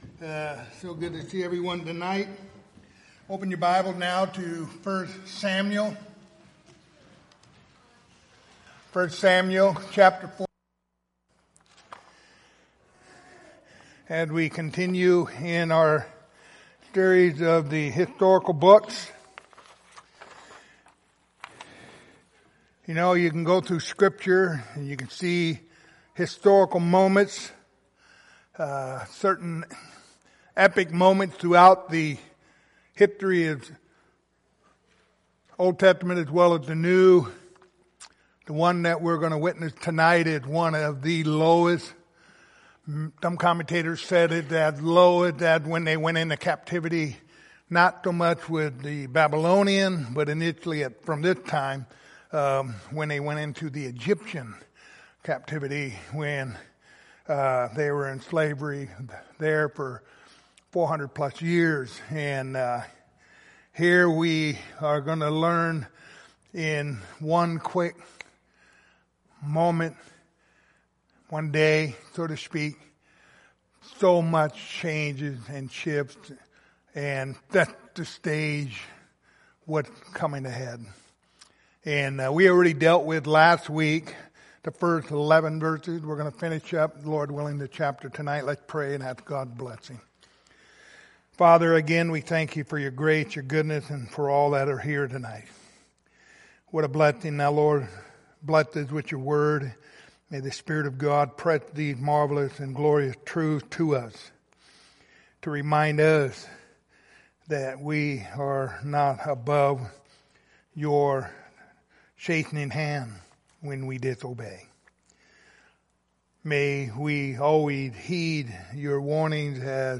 Passage: 1 Samuel 4:12-22 Service Type: Wednesday Evening